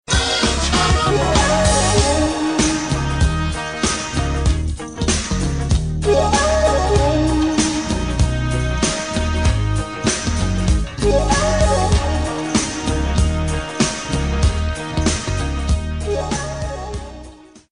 E-mu Emulator II shakuhachi